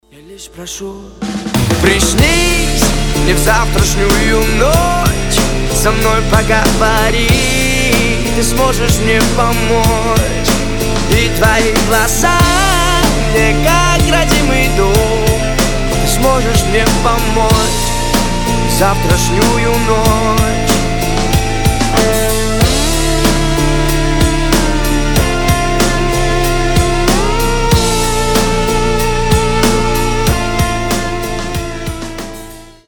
Романтические рингтоны
Спокойные